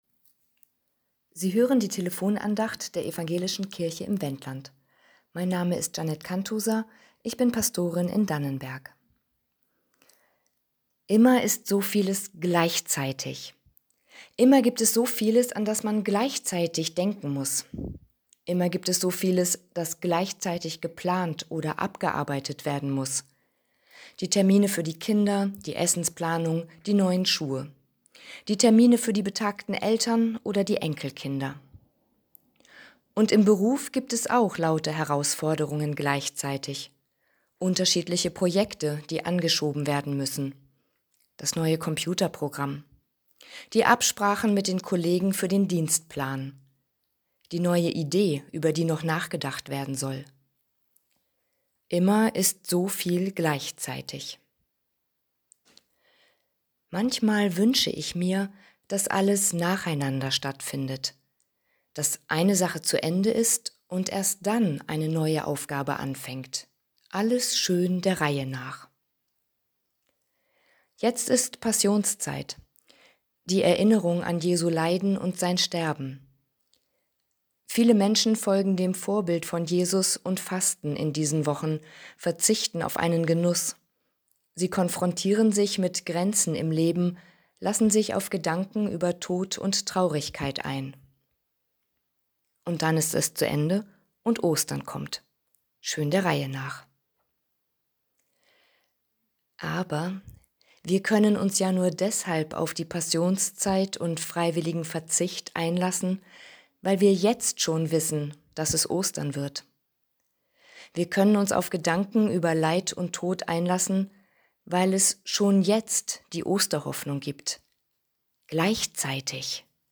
Telefon-Andachten des ev.-luth. Kirchenkreises Lüchow-Dannenberg